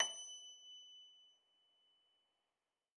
53l-pno24-F5.wav